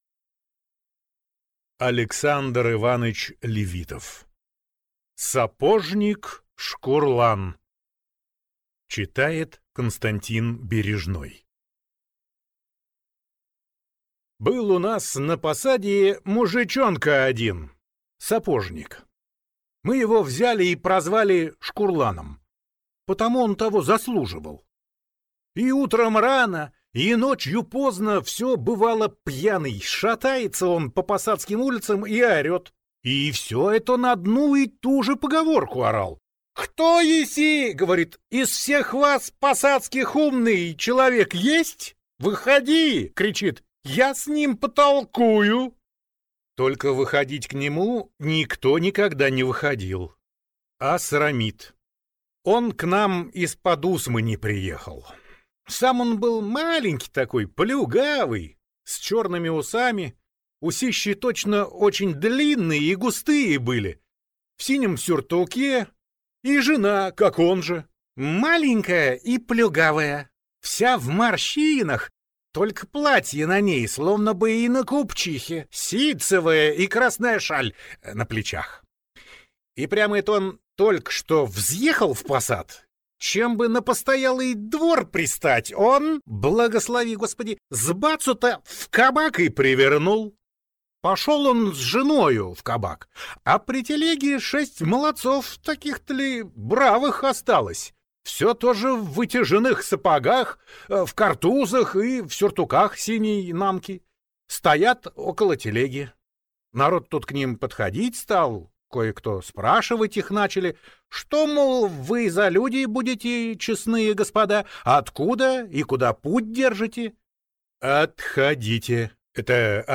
Аудиокнига Сапожник Шкурлан | Библиотека аудиокниг
Aудиокнига Сапожник Шкурлан